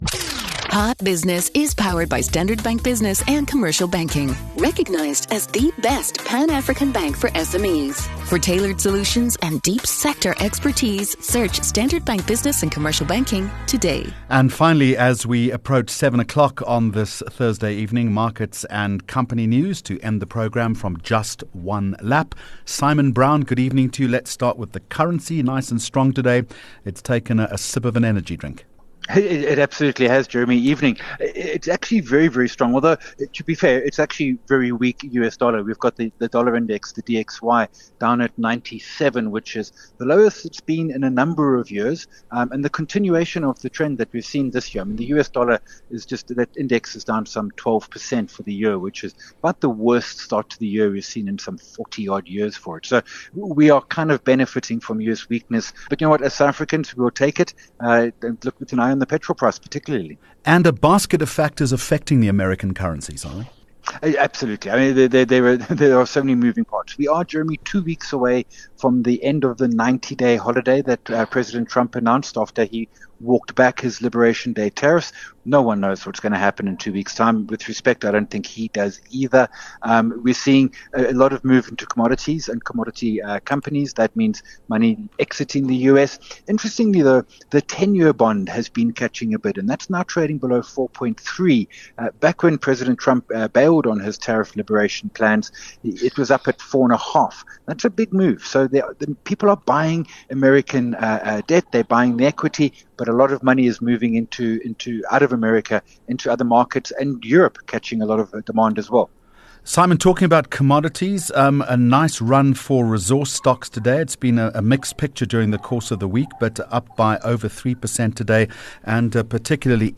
26 Jun Hot Business Interview